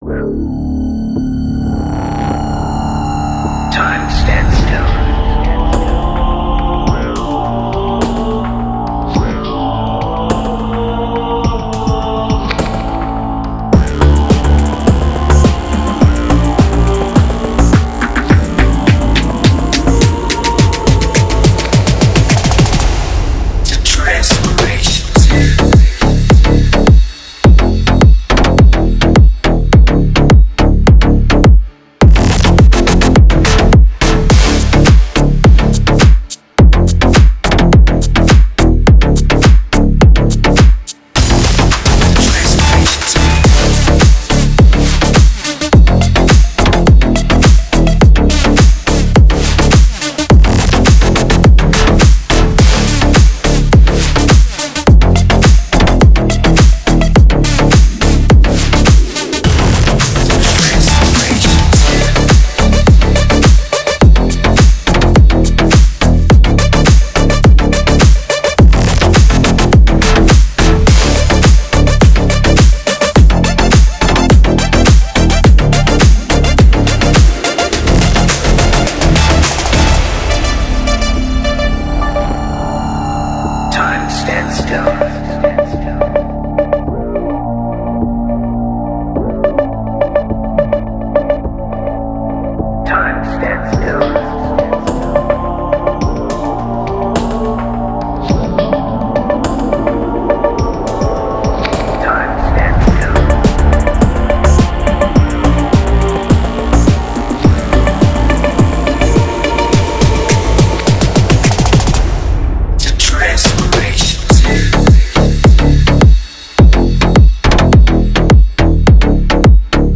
Категория: Клубная музыка